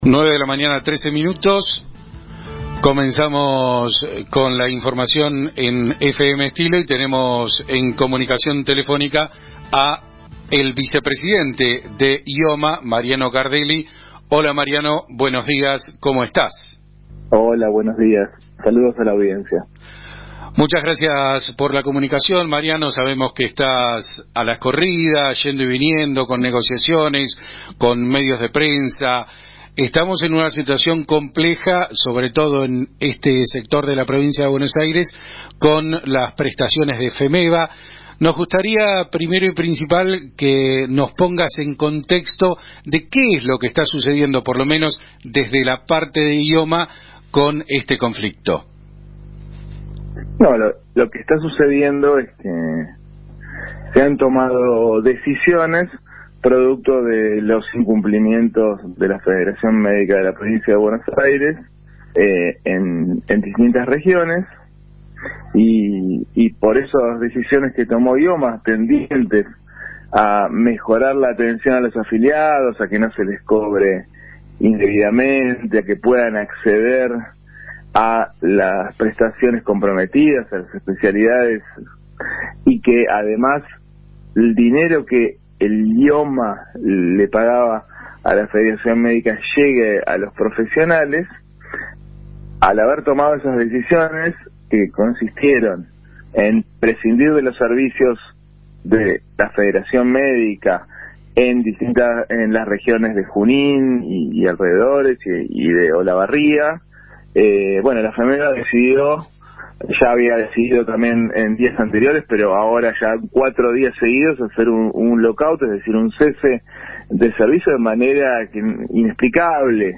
El vicepresidente de IOMA, Mariano Cardelli, habló en la radio Estilo Daireaux (FM 107.5) sobre las acciones que la obra social está llevando a cabo en respuesta al lock-out dispuesto por 96 horas por parte de la Federación Médica de la Provincia de Buenos Aires (FEMEBA), que comenzó ayer y durará hasta el jueves 10 de octubre inclusive, afectando la atención médica en un centenar de distritos del territorio bonaerense.
Mariano-Cardelli-Radio-Estilo-Daireaux.mp3